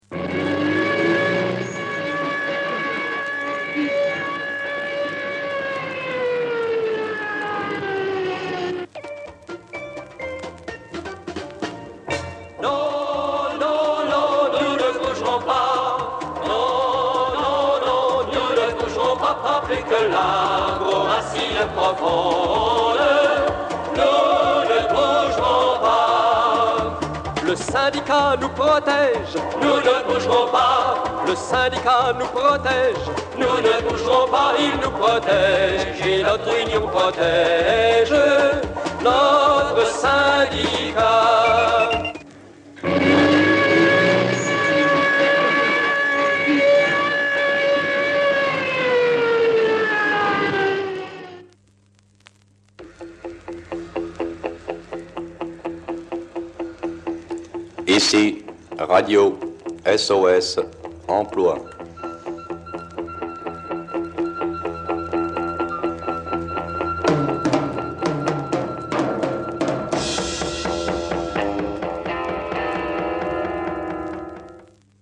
Disque 45 tours datant de 1979
avec des travailleurs.